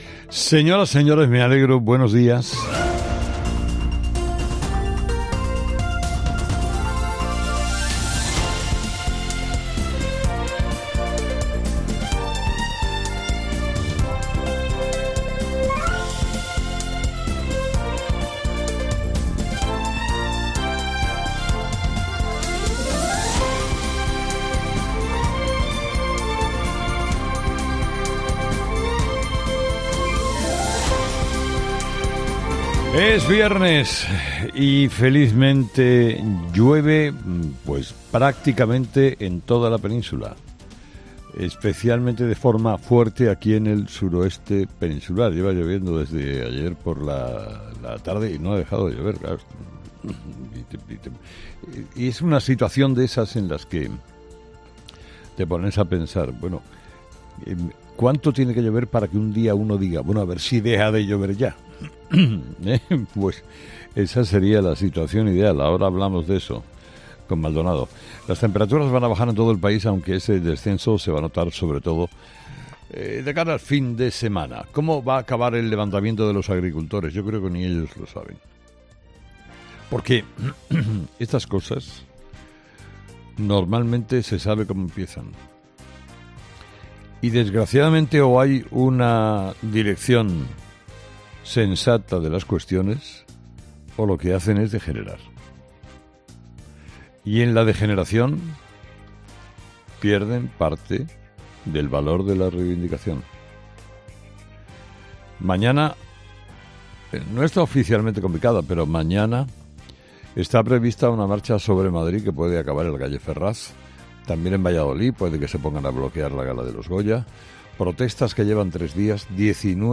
Escucha el análisis de Carlos Herrera a las 06:00 en Herrera en COPE del viernes 9 de febrero